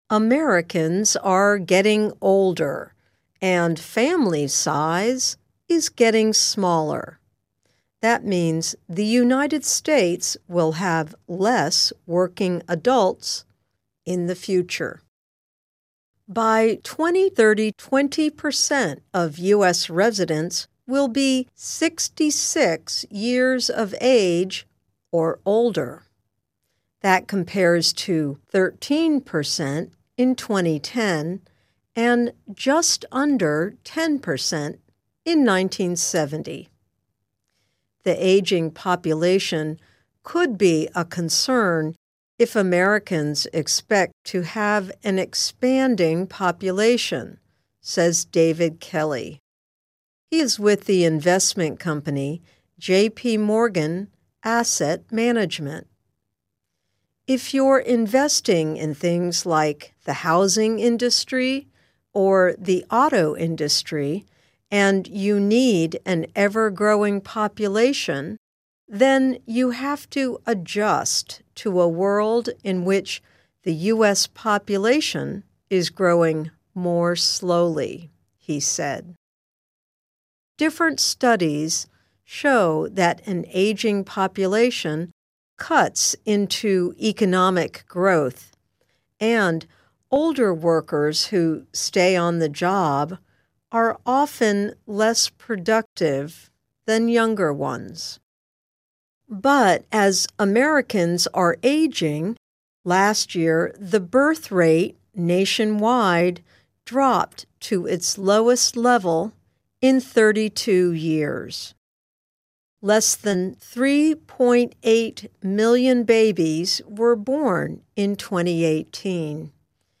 慢速英语:美国的老龄化意味着劳动力的减少